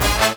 68_08_stabhit-A.wav